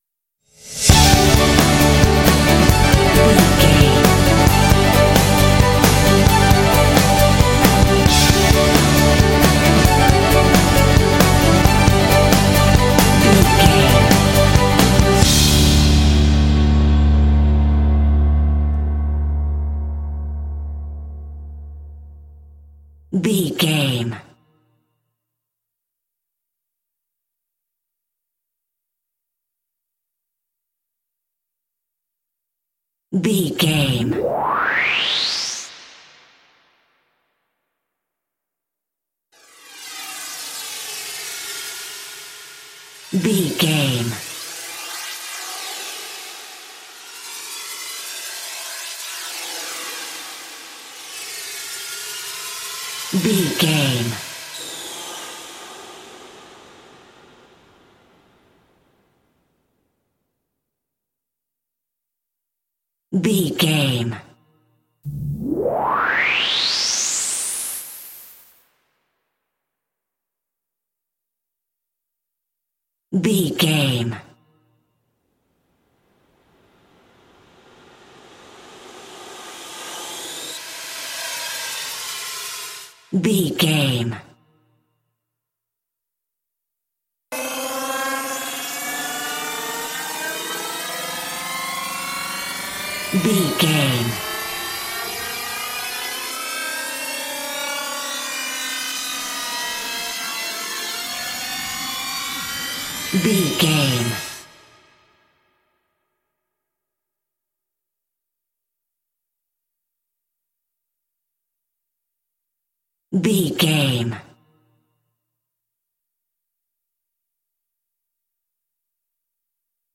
Bright and motivational music with a great uplifting spirit.
In-crescendo
Ionian/Major
epic
uplifting
powerful
strings
orchestra
percussion
piano
drums
rock
contemporary underscore